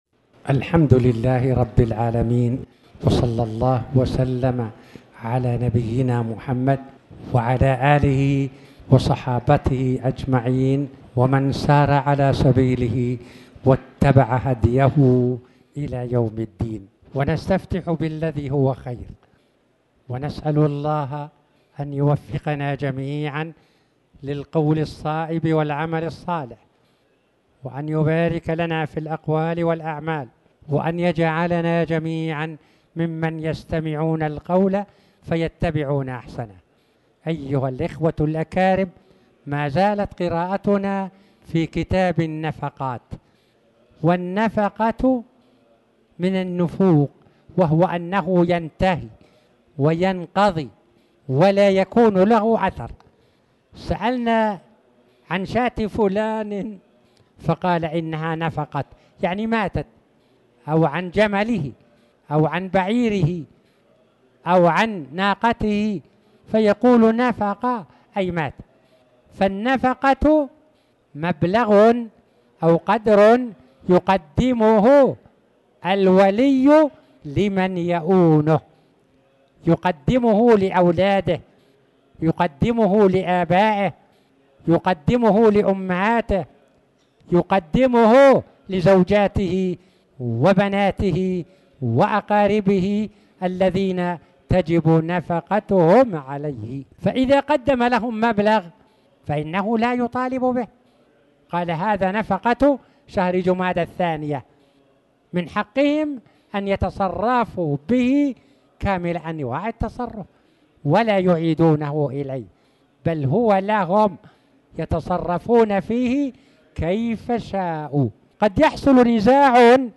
تاريخ النشر ٩ جمادى الآخرة ١٤٣٩ هـ المكان: المسجد الحرام الشيخ